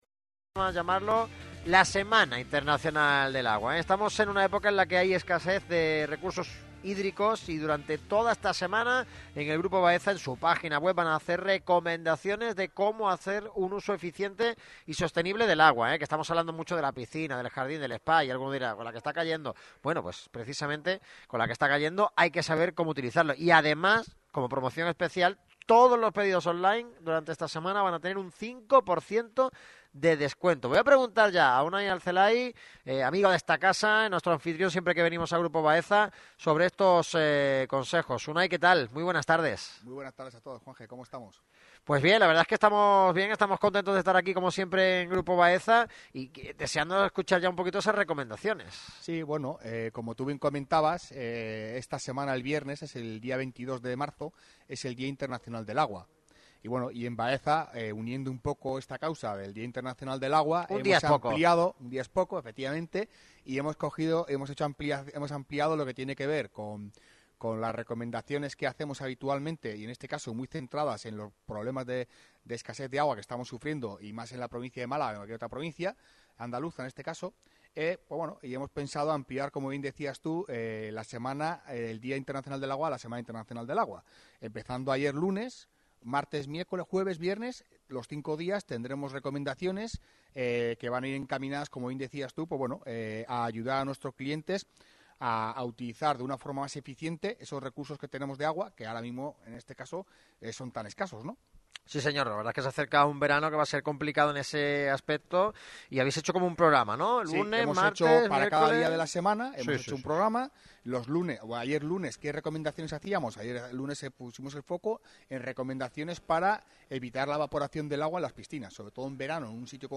El equipo de Radio MARCA Málaga ha visitado a Grupo Baeza, empresa líder en productos y soluciones sostenibles del sector del agua en todos sus usos, justo en el corazón de la avenida Velázquez, próximo al Aeropuerto Pablo Ruiz Picasso de la capital, junto a la gran piscina de la autovía.